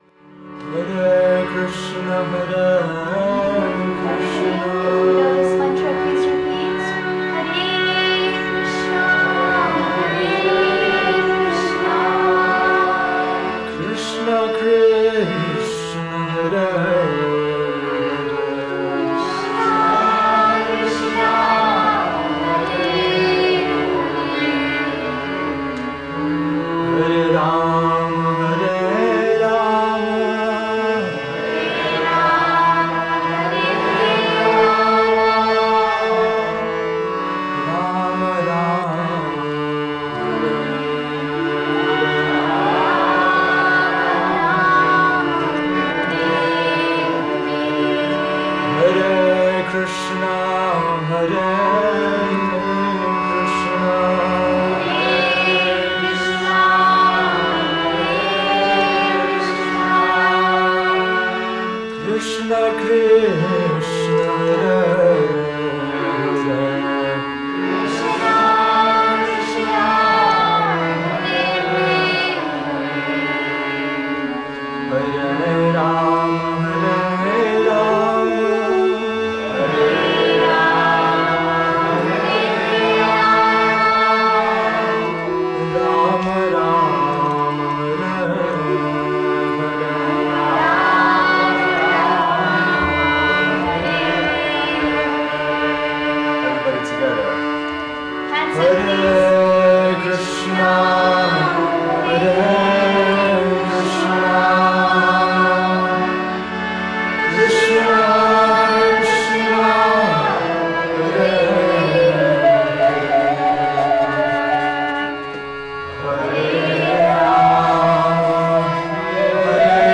Bhajan @ Chi Yoga